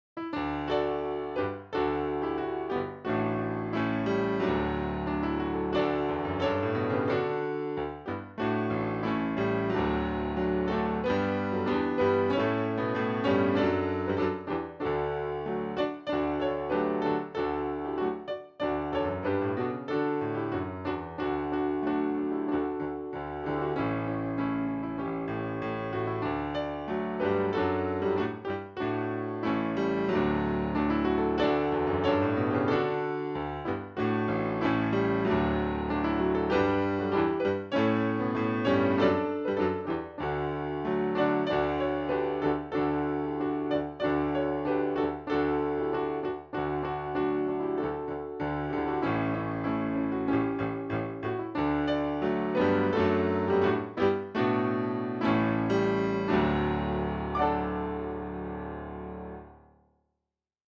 Key: D